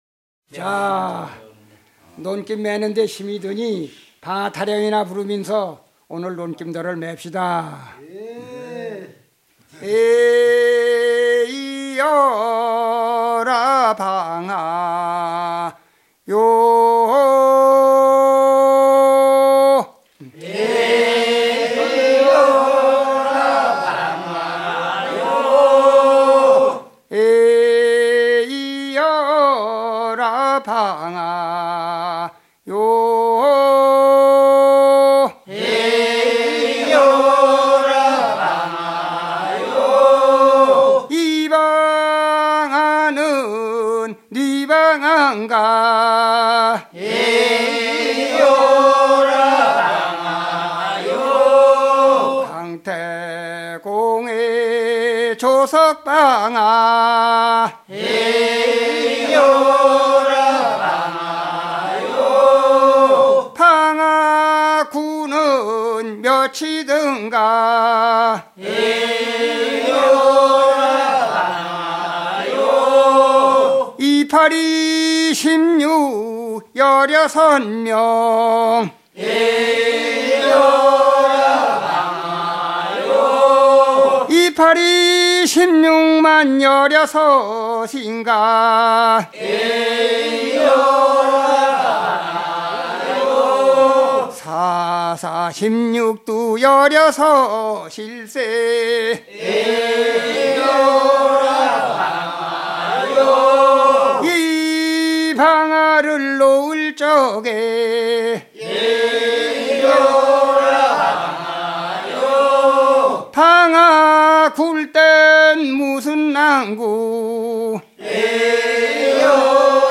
牛追い歌と農作業歌 - 畑歌は田歌、代掻き歌とともに江原道に特徴的に分布する民謡である。